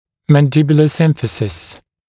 [ˌmæn’dɪbjulə ‘sɪmfəsɪs][ˌмэн’дибйулэ ‘симфэсис]нижнечелюстной (подбородочный) симфиз